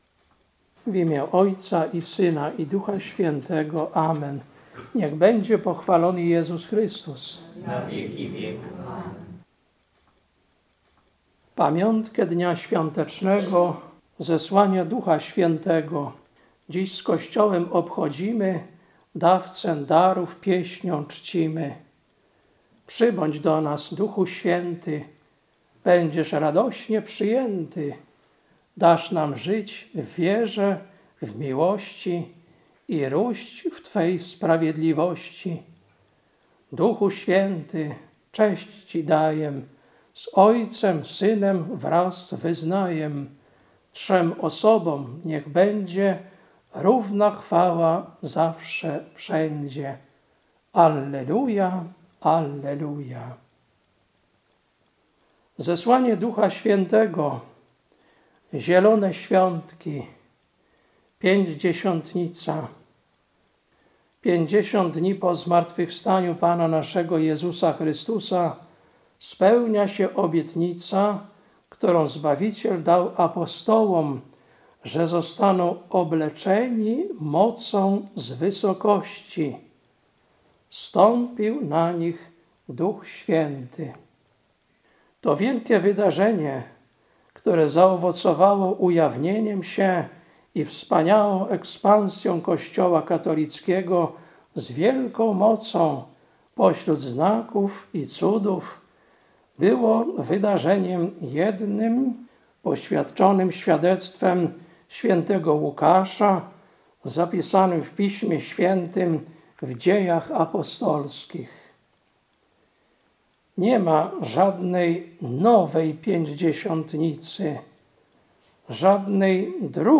Kazanie na Zesłanie Ducha Świętego, 8.06.2025 Lekcja: Dz 2, 1-11 Ewangelia: J 14, 23-31